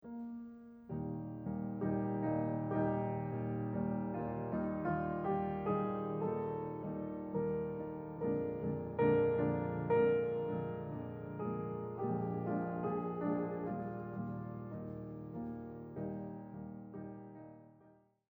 This whole start has a very intimate feeling, a tenderness in character that I love.